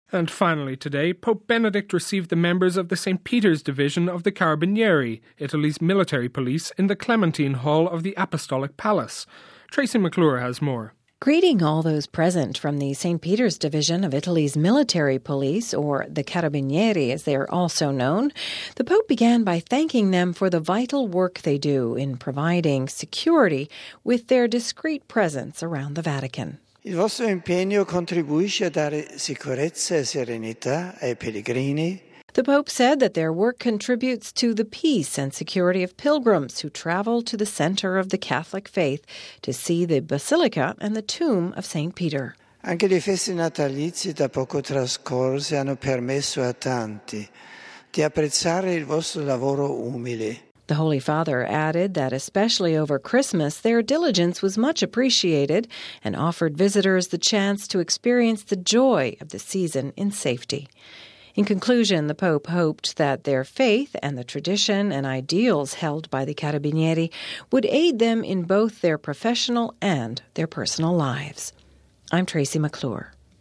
(07 Jan 09 - RV) Pope Benedict today received the members of the St Peter’s division of the Carabinieri, Italy’s military police in the Clementine Hall of the Apostolic palace: